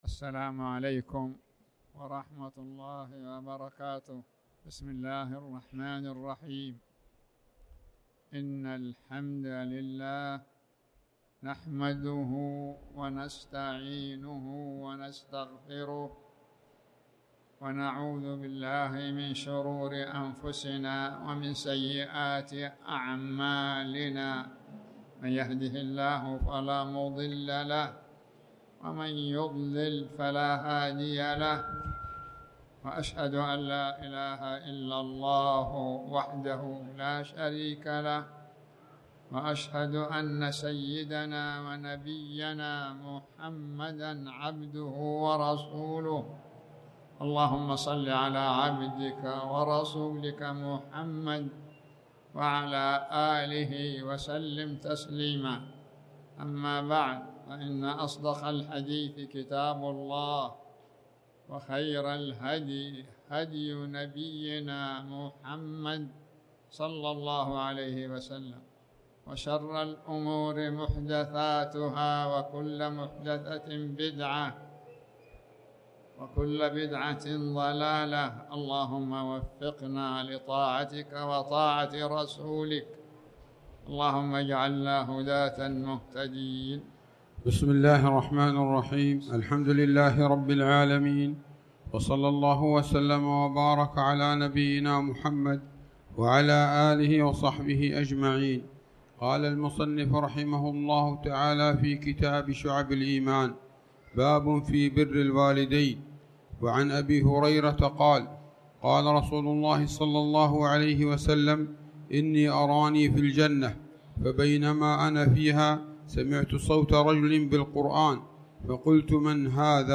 تاريخ النشر ٢٤ شوال ١٤٣٩ هـ المكان: المسجد الحرام الشيخ